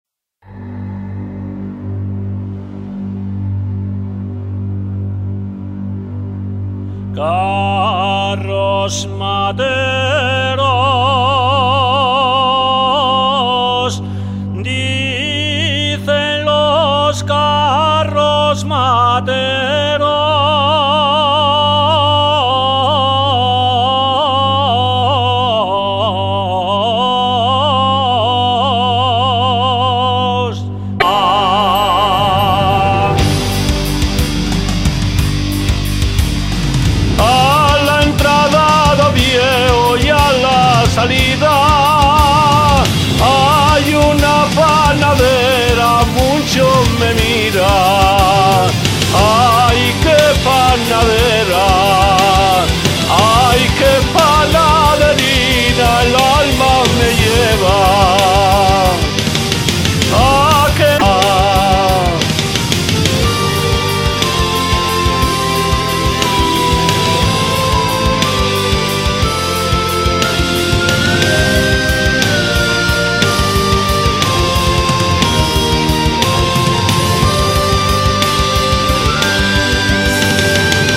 Tonada rock, estilo celtibermetal.